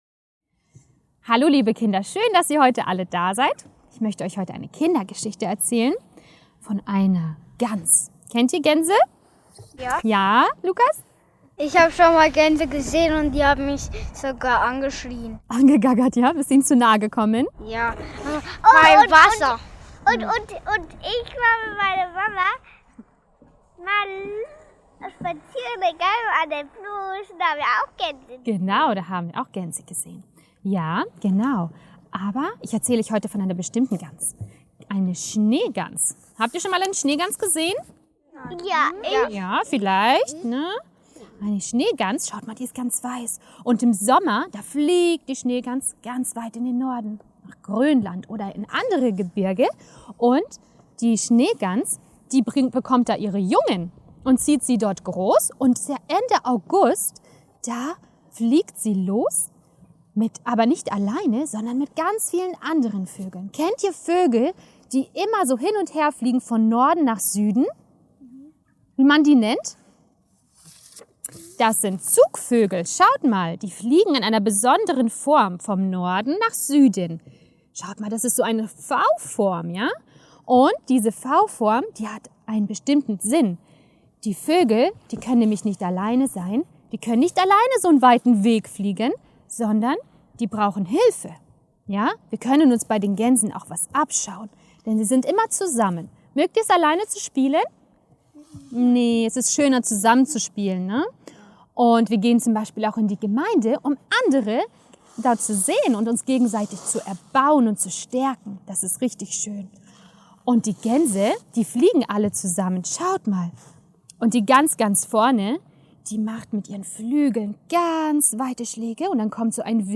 Wahre Kurzgeschichten für Kinder